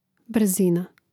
brzìna brzina